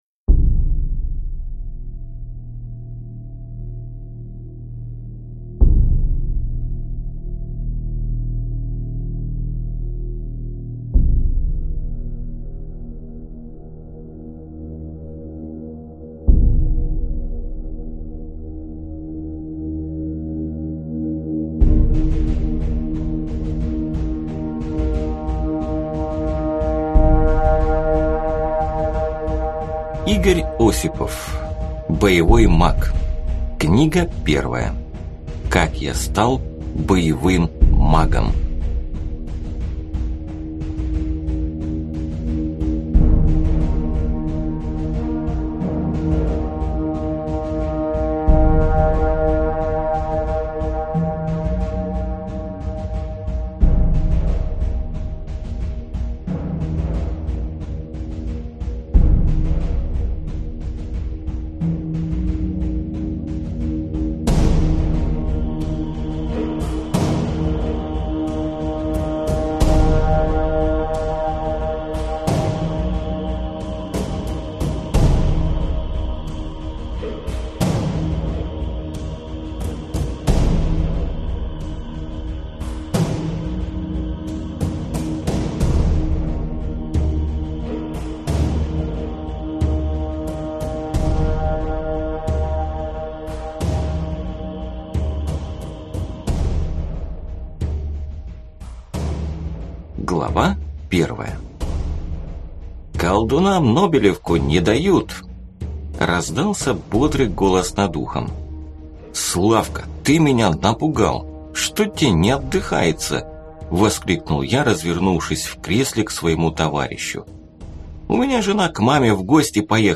Аудиокнига Как я стал боевым магом | Библиотека аудиокниг